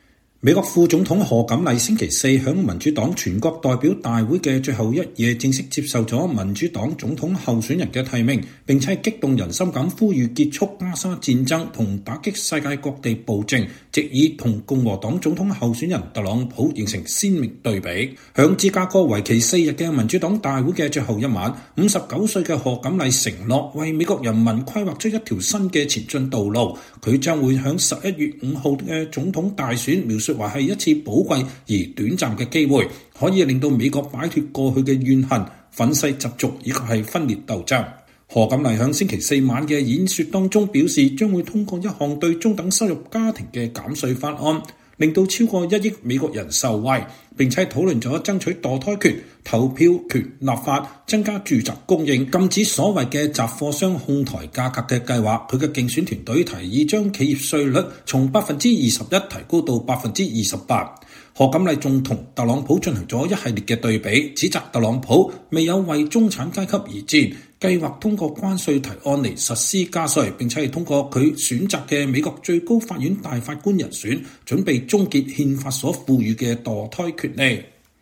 美國副總統賀錦麗(Kamala Harris)星期四(8月22日)在民主黨全國代表大會的最後一夜正式接受了民主黨總統候選人的提名，並在演說中呼籲結束加沙戰爭與打擊世界各地的暴政，希望藉此與共和黨總統候選人唐納德·特朗普(Donald Trump)形成鮮明對比。